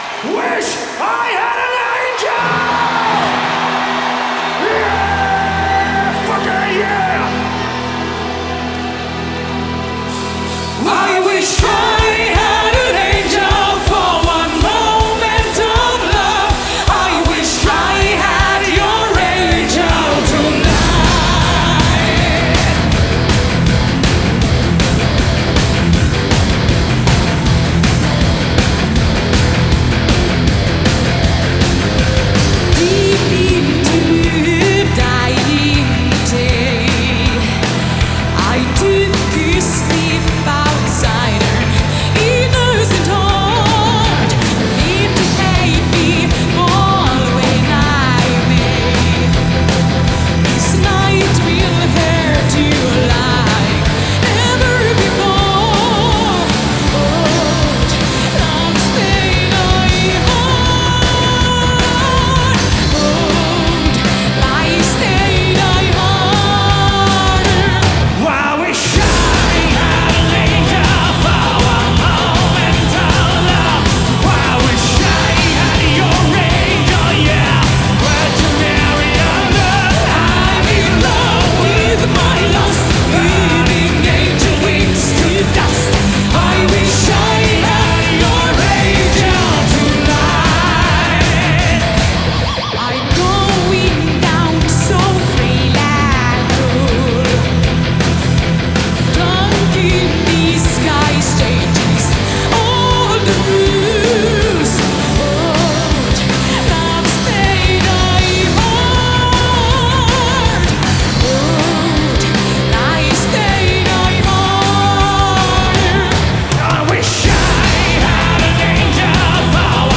Genre: Symphonic Rock